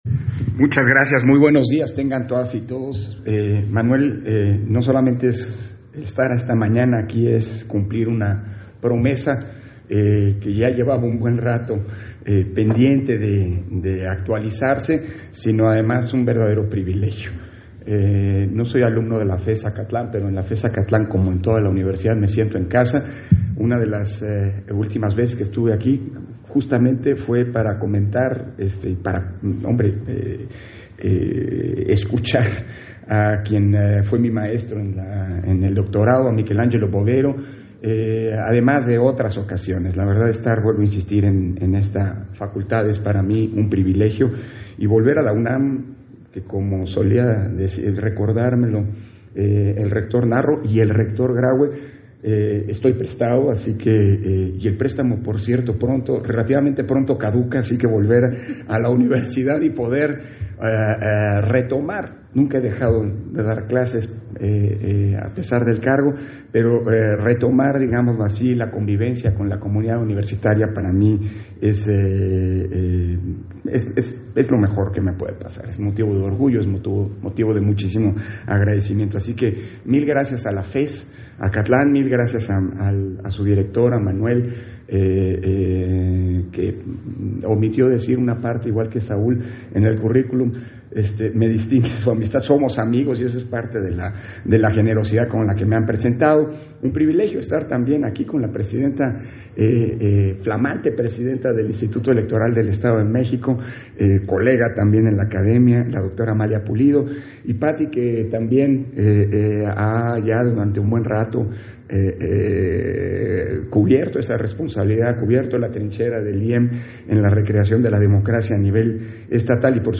Conferencia magistral dictada por Lorenzo Córdova, Evolución y desafíos de la democracia mexicana, en la FES Acatlán